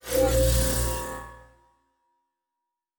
Teleport 8_1.wav